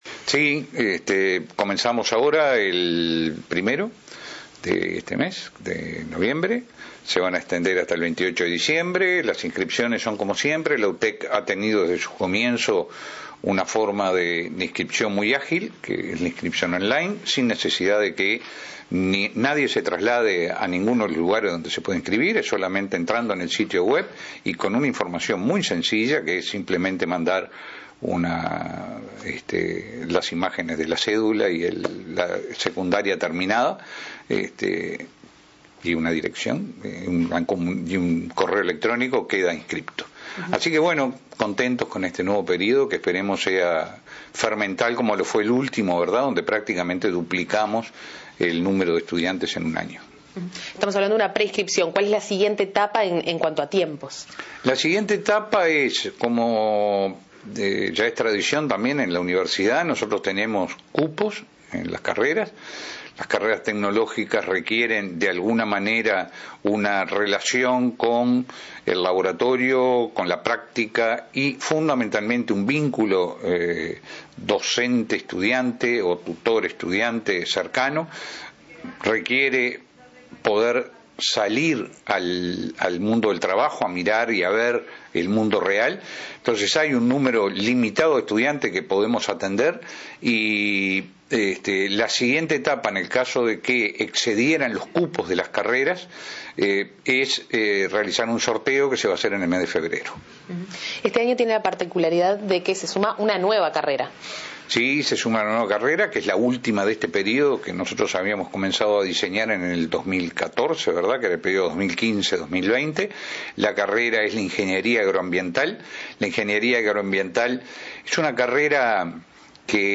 En entrevista con la Secretaría de Comunicación, adelantó que en 2019 se sumará una nueva carrera a su oferta educativa: Ingeniería Agroambiental. El jerarca subrayó que se pasó de 44 estudiantes en 2014 a 1.800 en 2018, lo que demuestra la necesidad insatisfecha que existía.